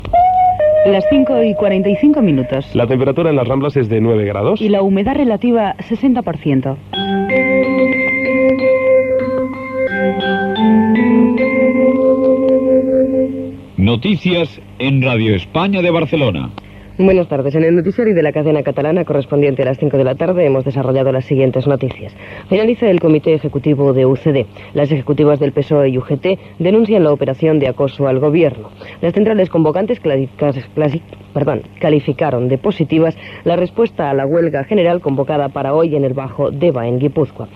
Hora exacta, dades meteorològiques, careta butlletí i notícia: vaga convocada per les centrals sindicals a Guipúscoa.
Informatiu